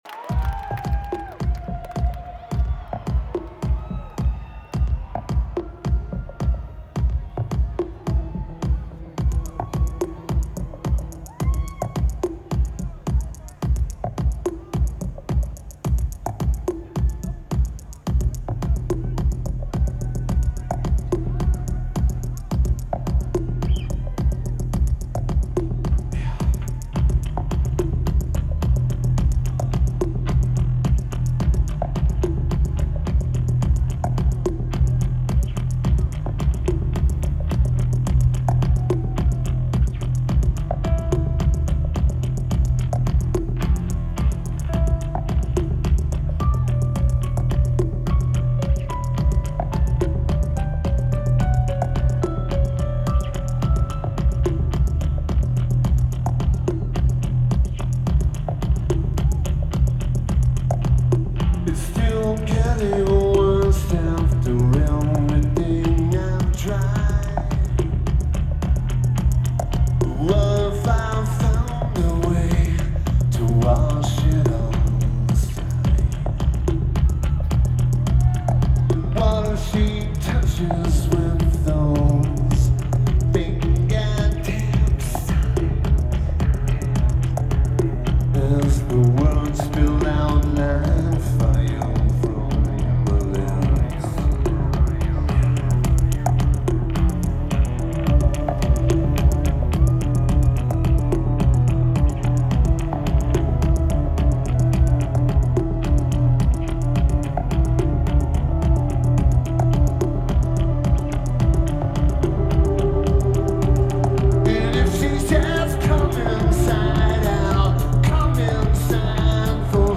Red Rocks Amphitheatre
Lineage: Audio - AUD (Sony PCM-A10)